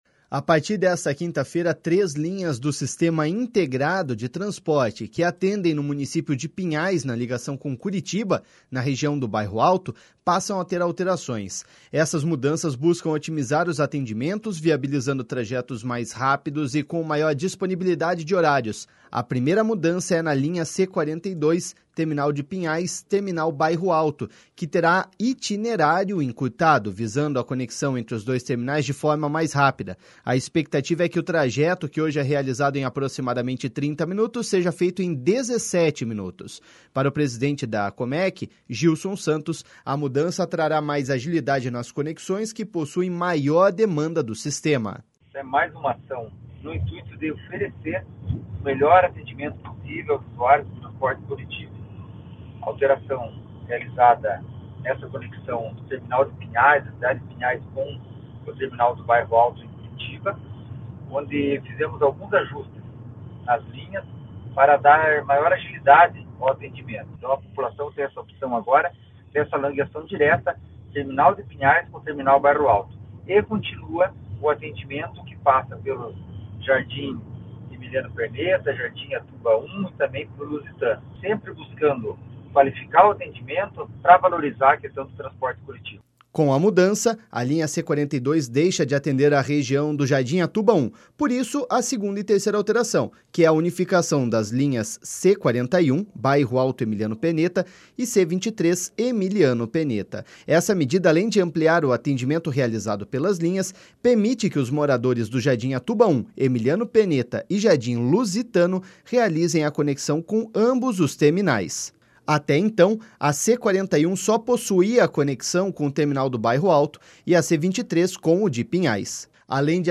Para o presidente da Comec Gilson Santos, a mudança trará mais agilidade nas conexões que possuem maior demanda do sistema.// SONORA GILSON SANTOS.//